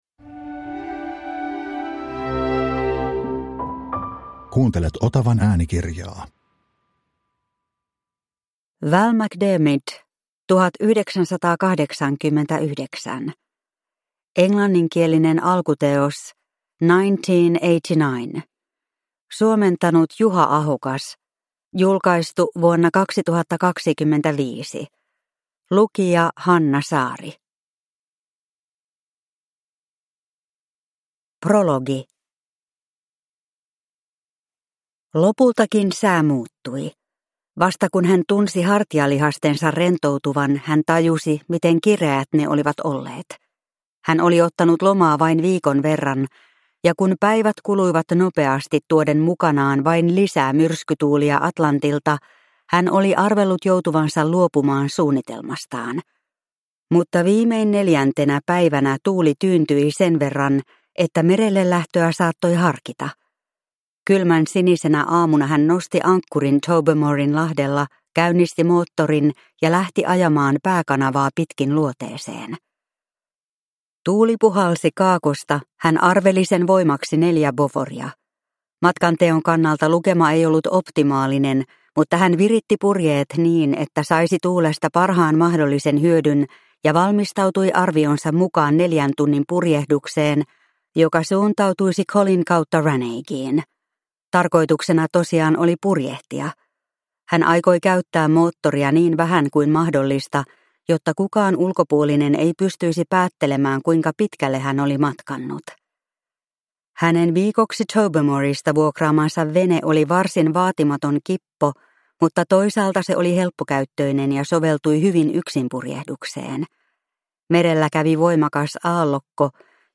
1989 – Ljudbok